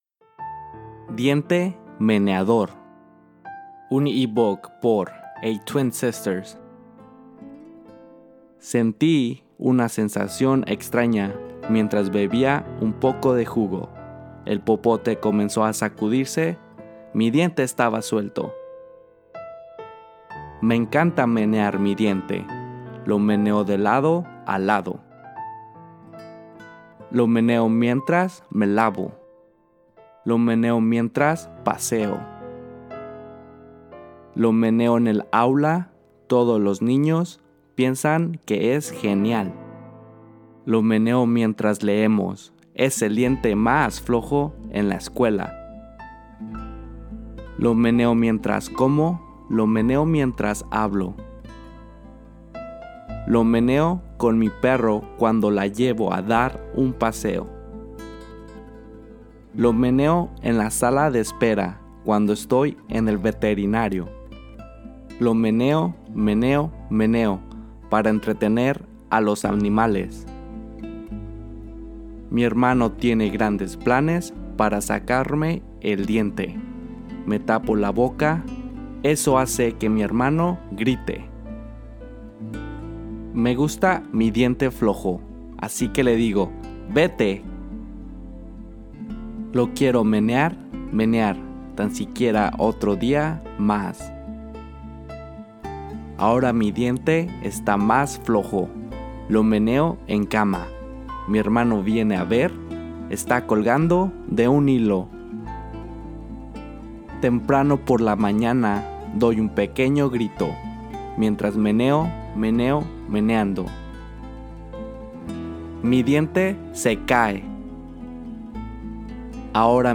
Reading Diente Meneador